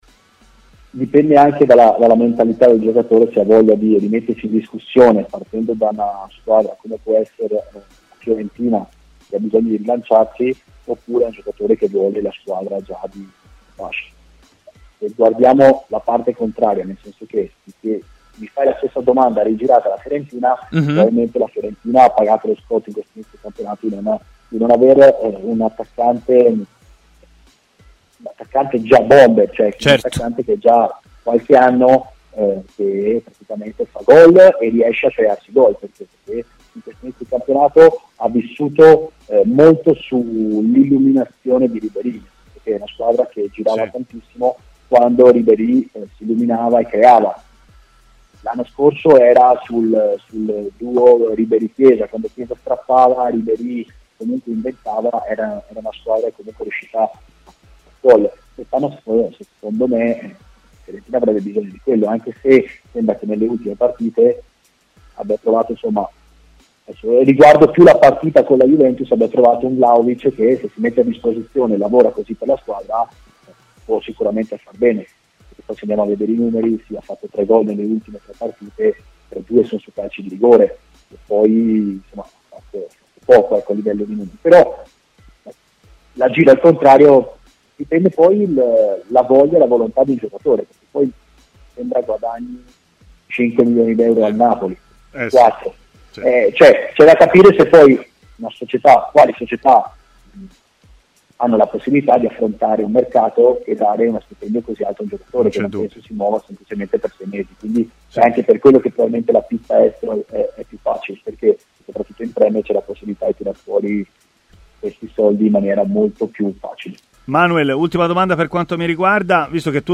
L'ex difensore Manuel Pasqual è intervenuto a TMW Radiol.